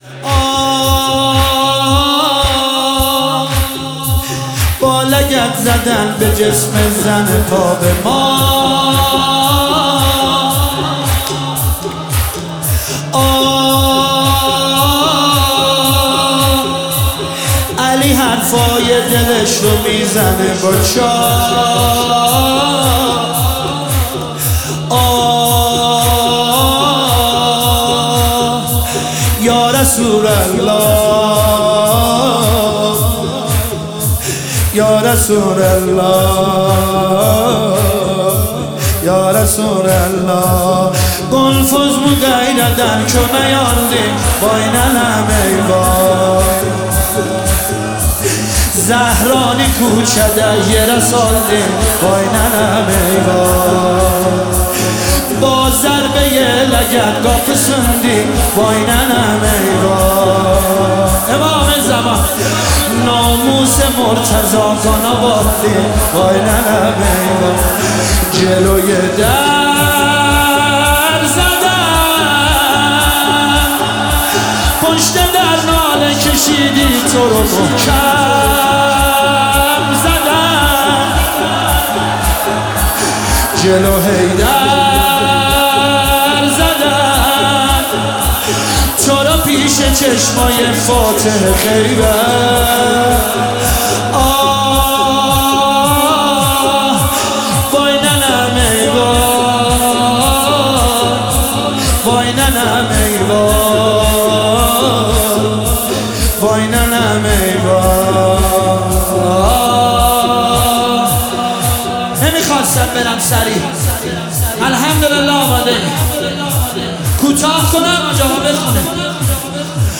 هیئت محفل زوار البقیع تهران